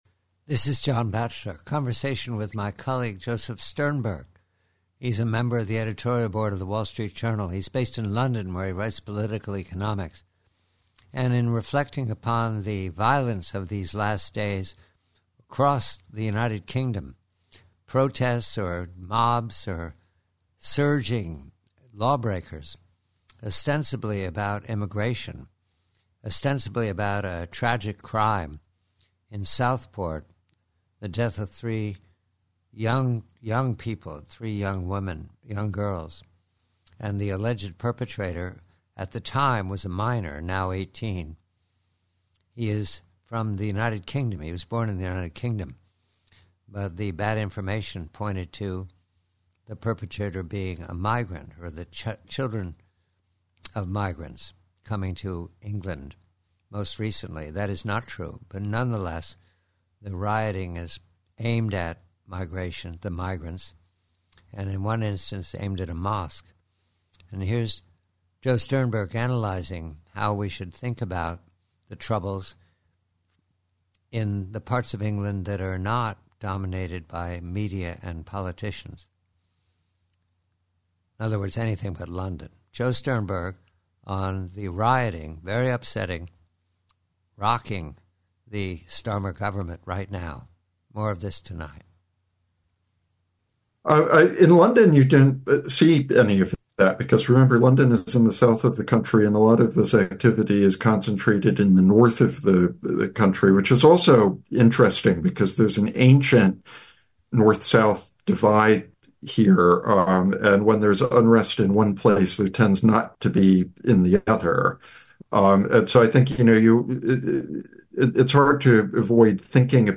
PREVIEW: UK ANTI-IMMIGRANT RIOTS: Conversation